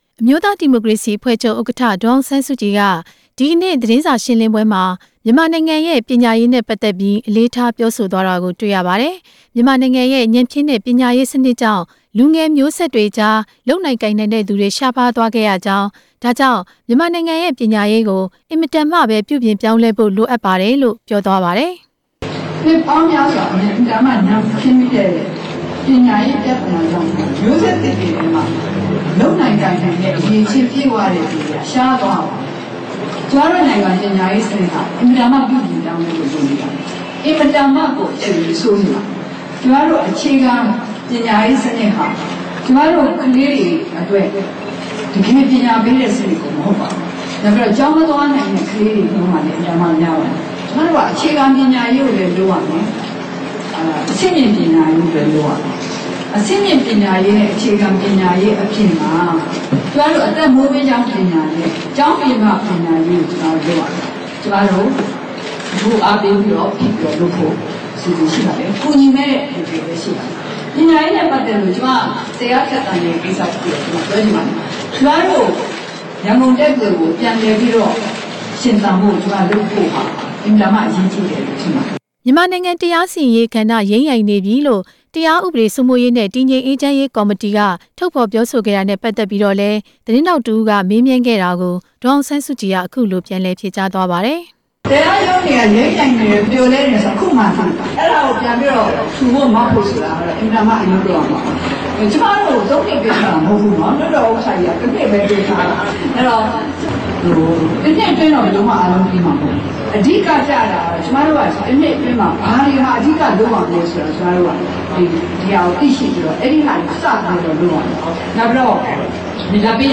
ဒေါ်အောင်ဆန်းစုကြည် အမေရိကန်ခရီးစဉ် သတင်းစာရှင်းလင်း